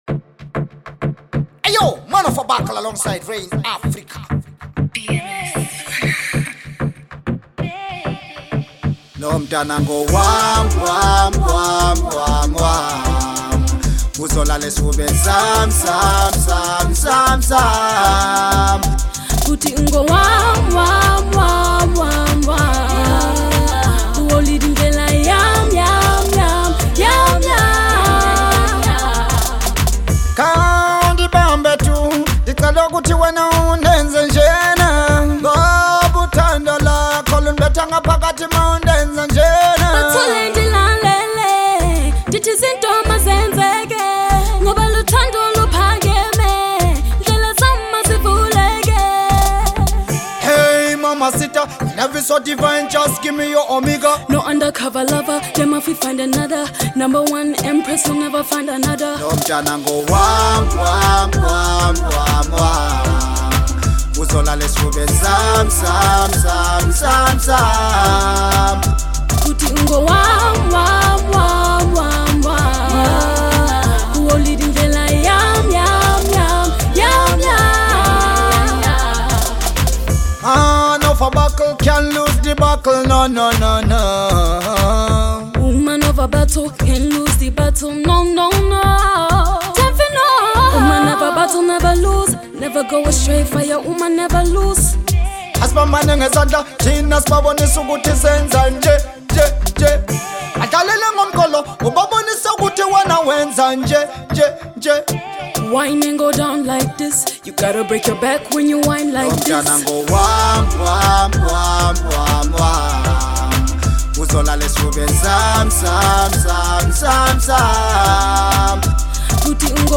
Genre: Reggae Dancehall